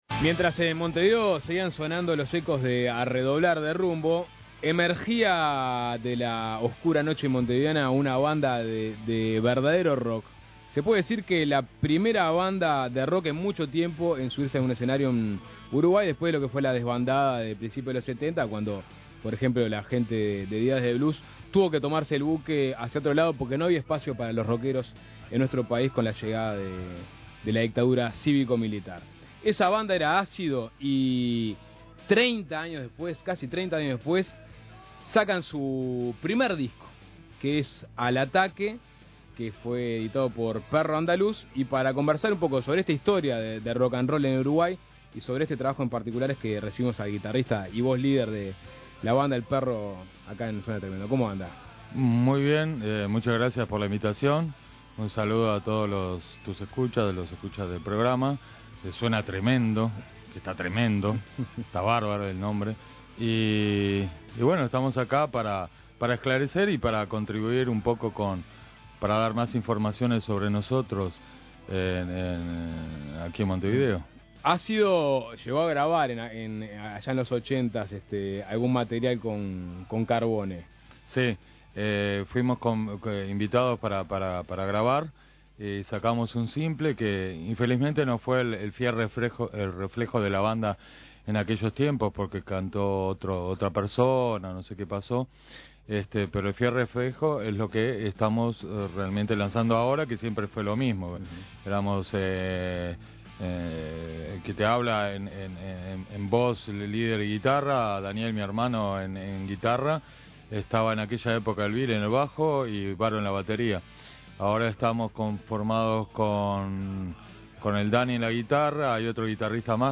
visitó el estudio de Suena Tremendo para presentar el nuevo disco "Al ataque" y de paso compartir algunas anécdotas.